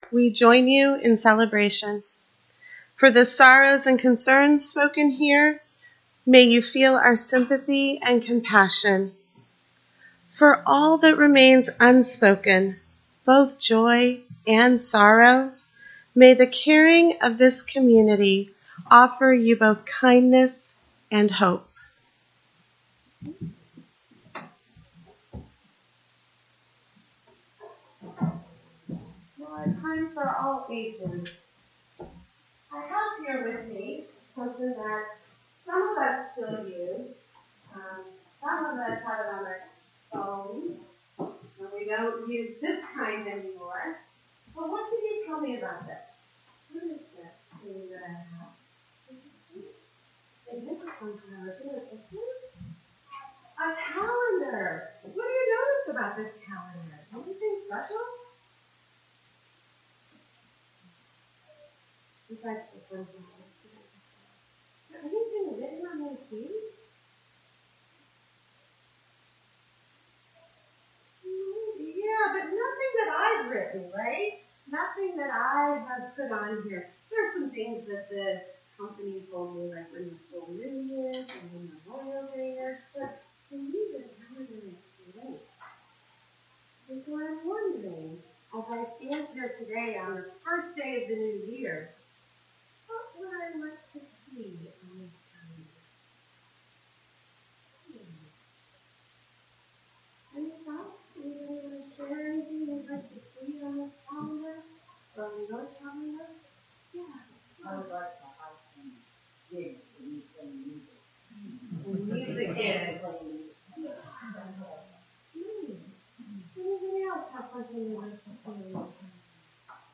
This sermon captures a UUCL’s New Year’s service centered on the Burning Bowl ceremony, a ritual designed for spiritual renewal.